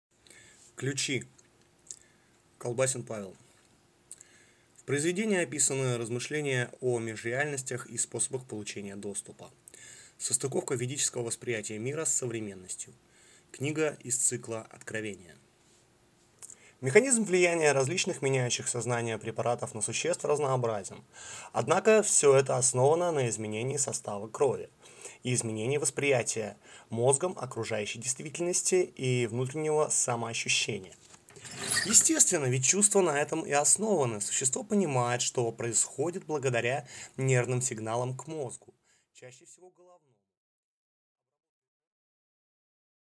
Аудиокнига Ключи | Библиотека аудиокниг